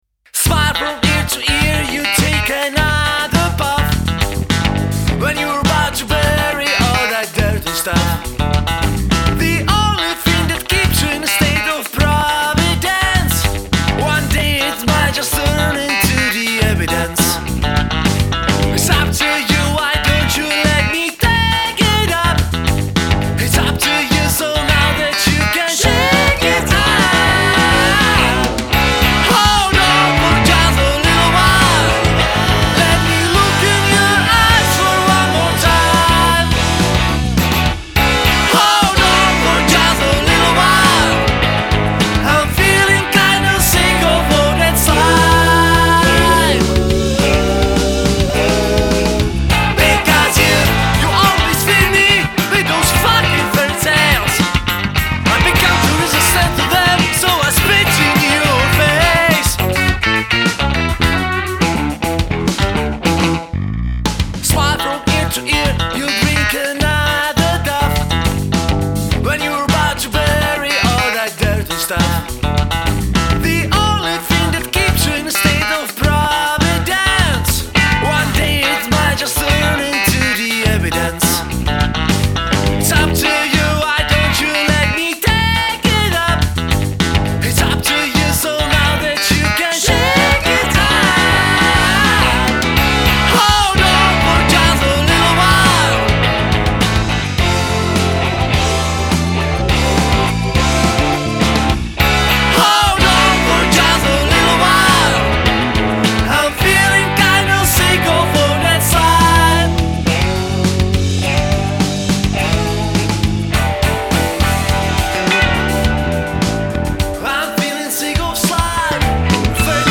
Zkusil jsem udělat country písničku a takhle to dopadlo big_smile